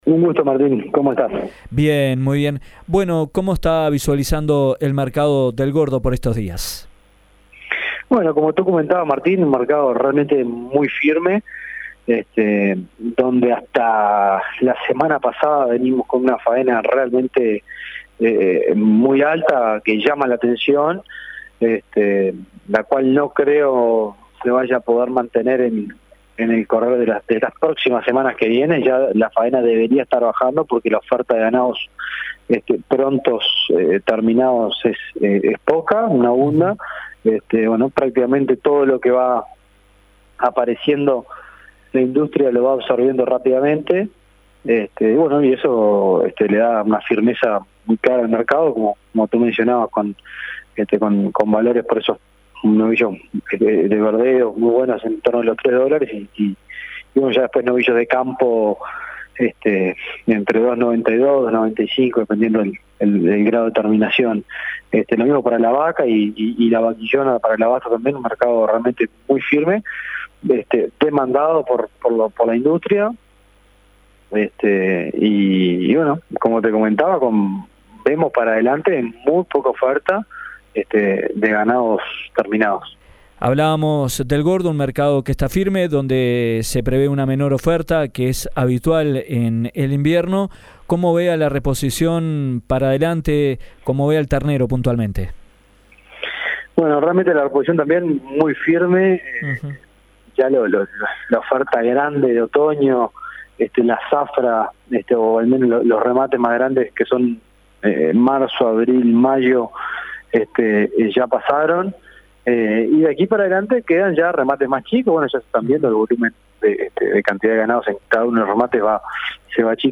dialogó con Dinámica Rural de El Espectador